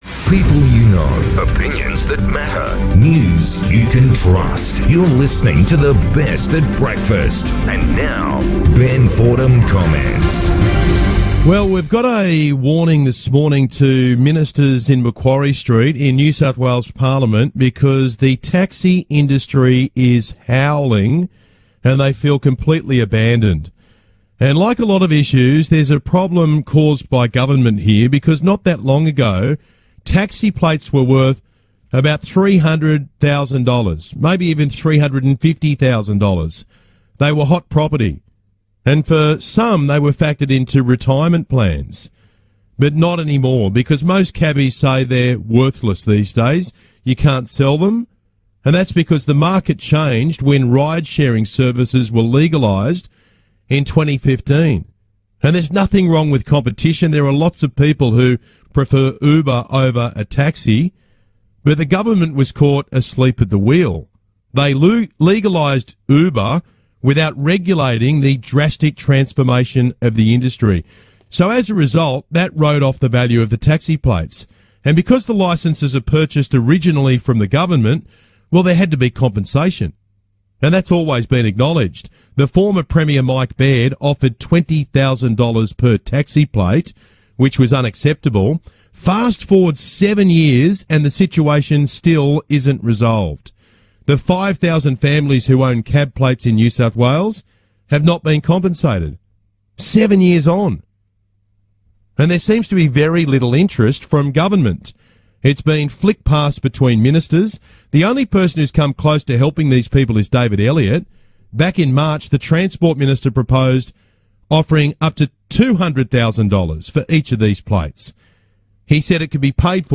Friday 29th July, 2022 Source - 2GB Radio 2GB Breakfast host Ben Fordham is asking the NSW Government why compensation for NSW Taxi Licence owners has not been resolved. Ben highlighted that NSW Taxi Licence owners have been waiting for 7 years for compensation and have received nothing to date.